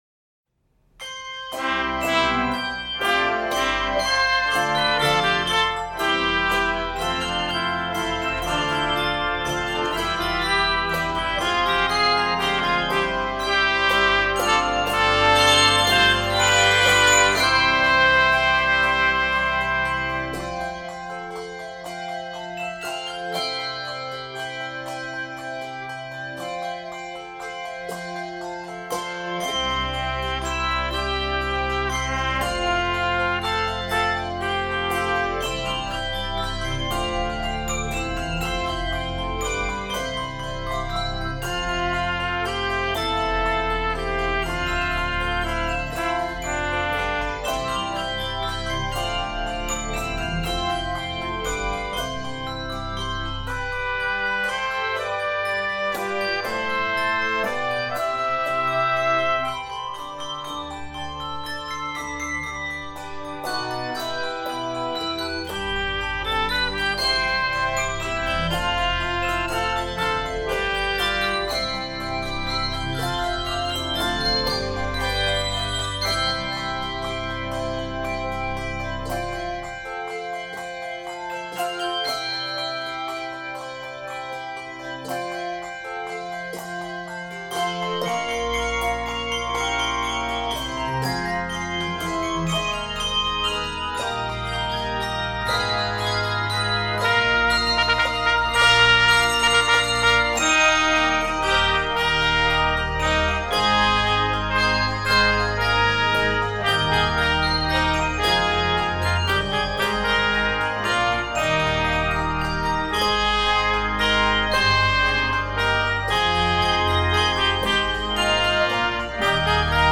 handbells
Keys of C Major and Eb Major.